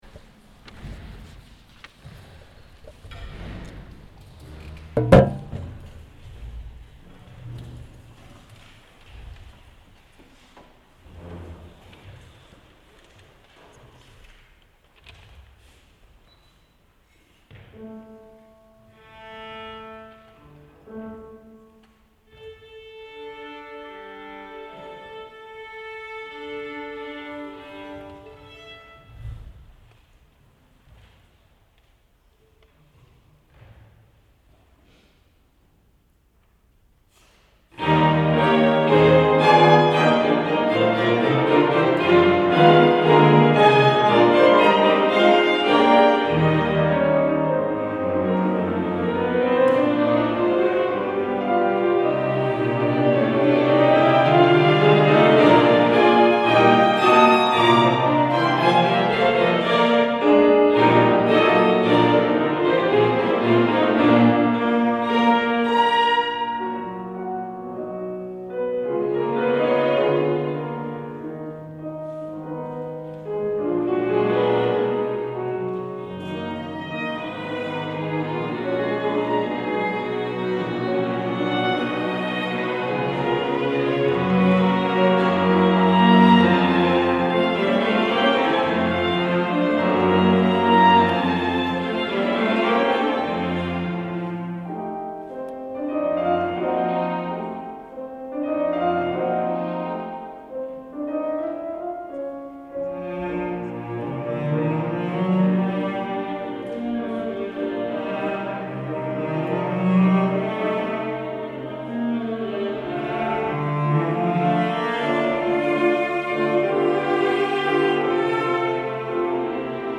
Emerging Artists concert July 16, 2013
Allegro brillante
violin
viola
cello
piano
schumann_piano_quintet.mp3